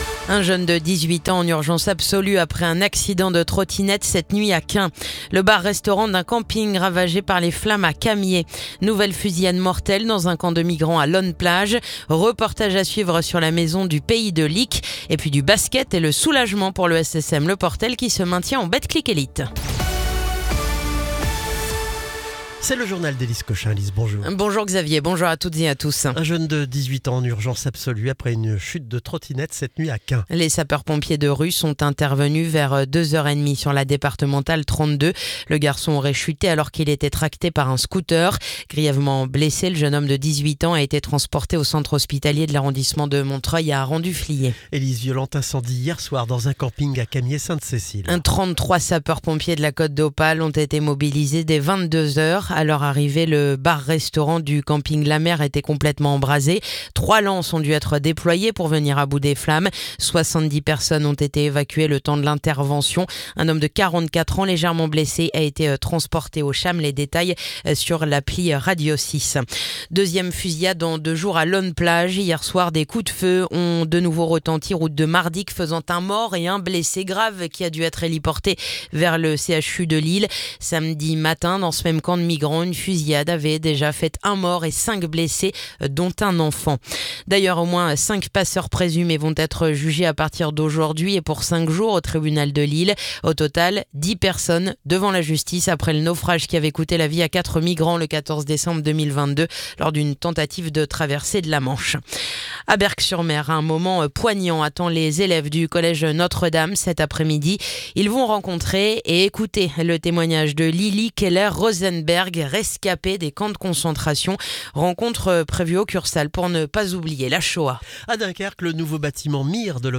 Le journal du lundi 16 juin